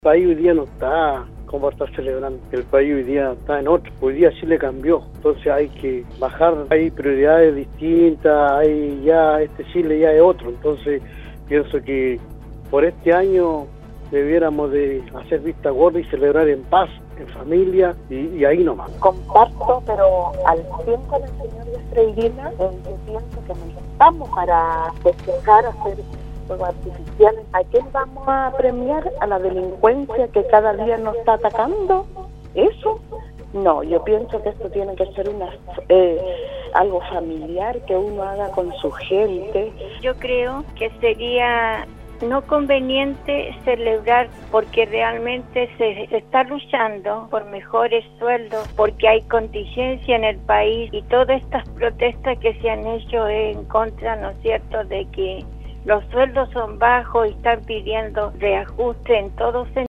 La mañana de este martes, auditores de Nostálgica participaron en el foro del programa Al Día donde opinaron en relación a sí están o no de acuerdo con la realización de los tradicionales fuegos artificiales en fin de año en las nueve comunas de la Región de Atacama.